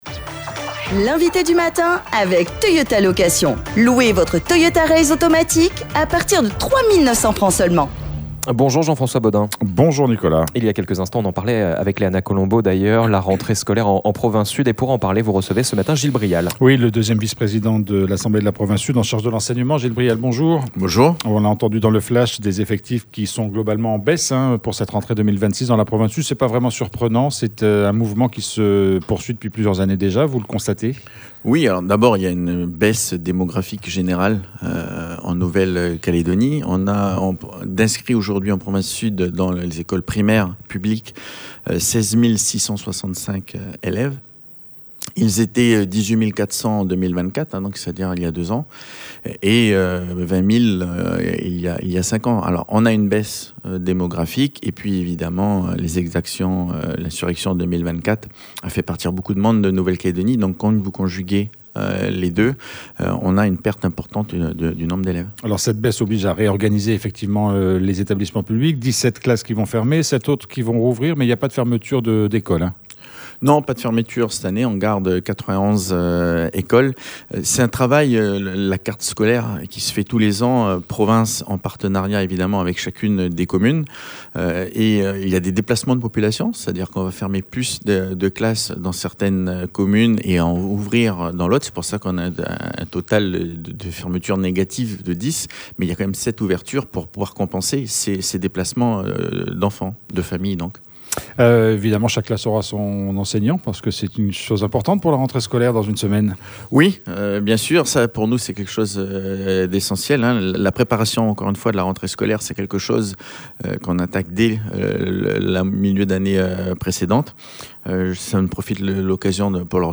L'INVITE DU MATIN : GIL BRIAL
Nous recevions Gil Brial, 2ème vice-président de la Province sud en charge de l’enseignement. Quelles sont les nouveautés de cette année 2026 pour la province ? Nous avons fait avec lui un tour d’horizon des points forts de cette rentrée.